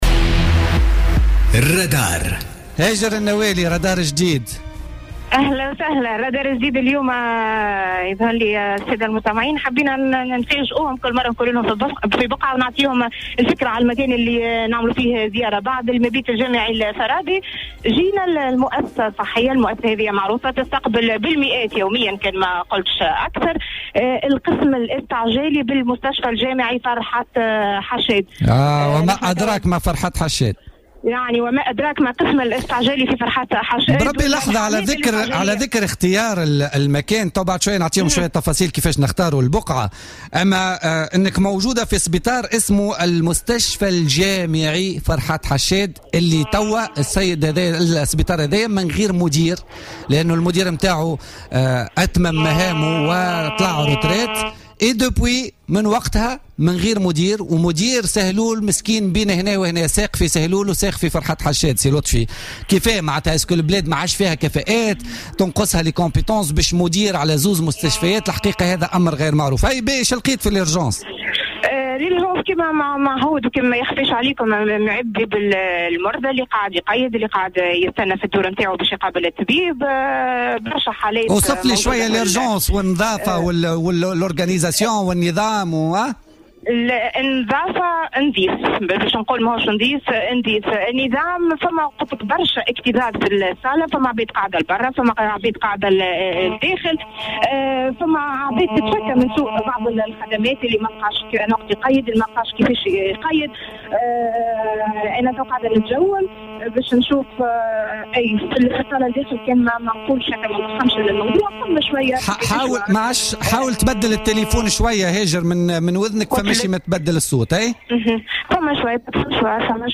قال مواطن التقته الجوهرة أف أم اليوم الثلاثاء في قسم الطوارئ بالمستشفى الجامعي...